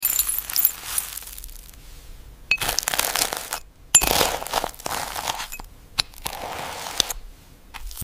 ASMR Fantasy Toast Ai Blue sound effects free download
ASMR Fantasy Toast Ai Blue Butterfly Crystals Sprinkles Toast ASMR